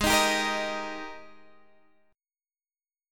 G#M7sus2 chord